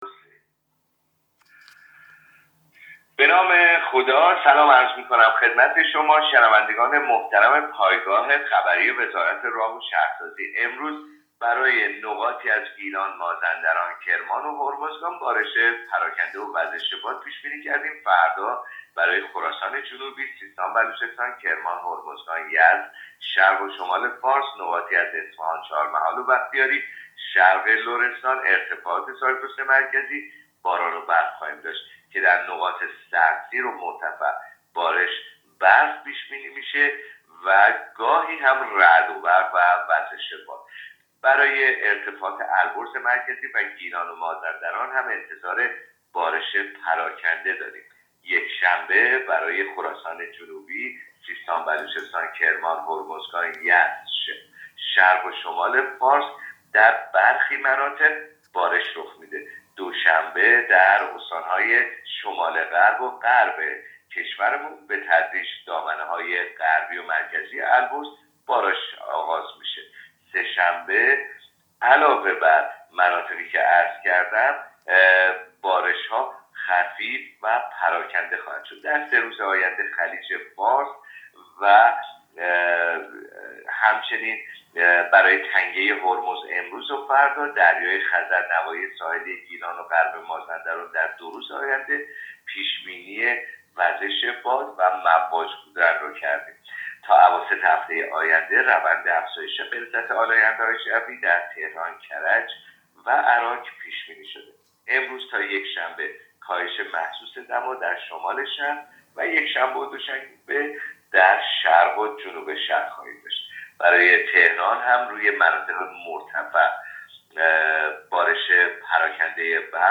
گزارش رادیو اینترنتی پایگاه خبری از آخرین وضعیت آب‌وهوای ۱۸ آذر؛